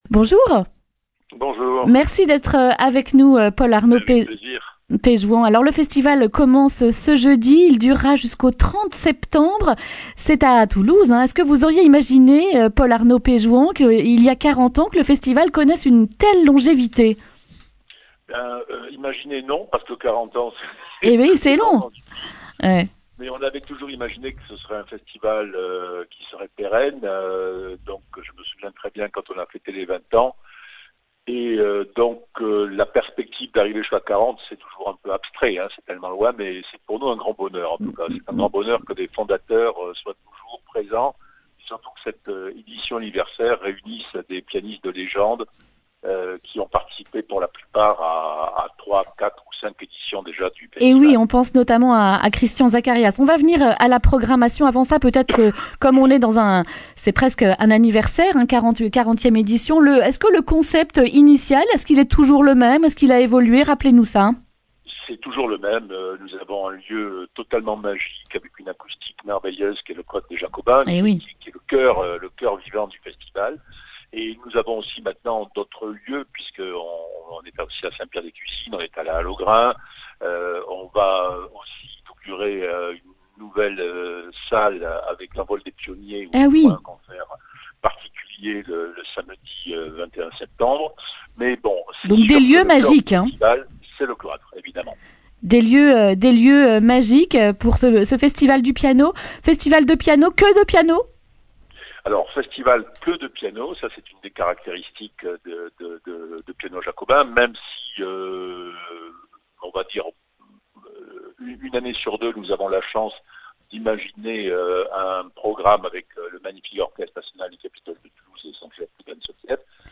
mercredi 4 septembre 2019 Le grand entretien Durée 11 min